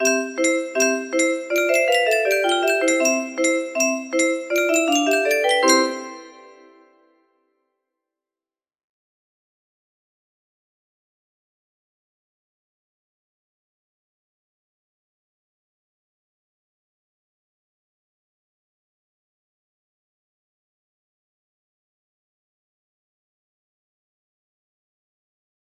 Bateau sur l'eau music box melody